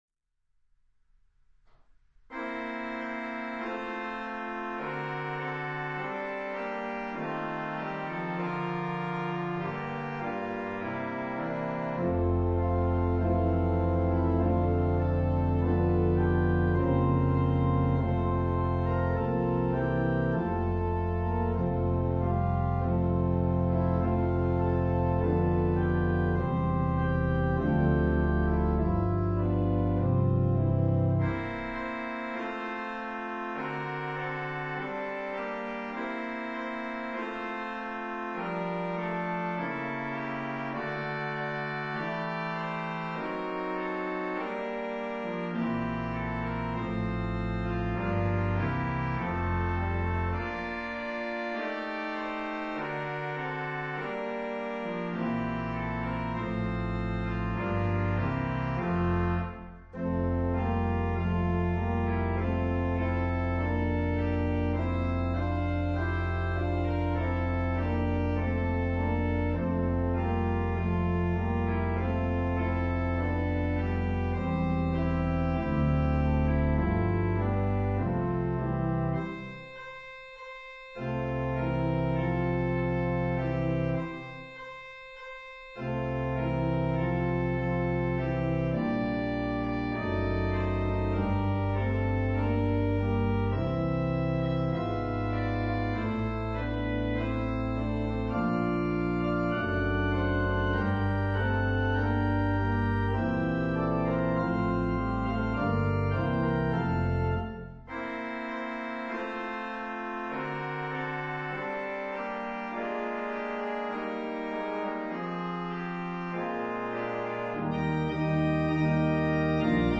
A triumphant setting of
Christmas